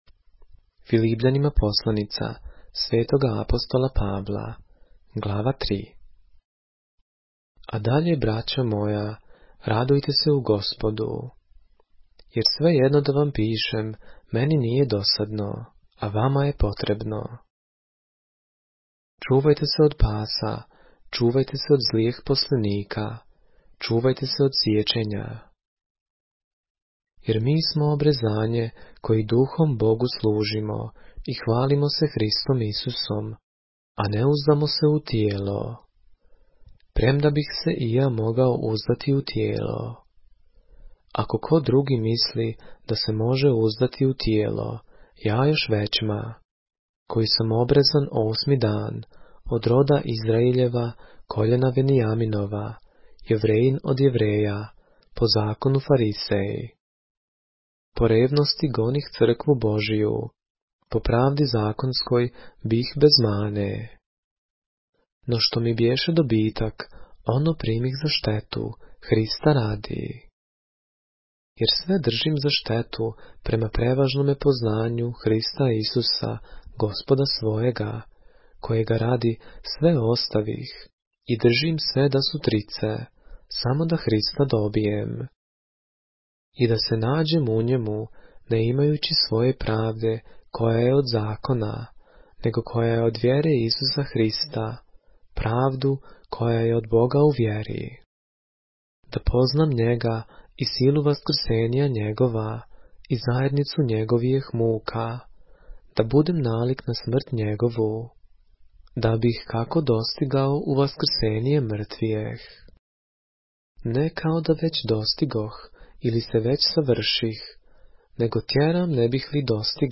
поглавље српске Библије - са аудио нарације - Philippians, chapter 3 of the Holy Bible in the Serbian language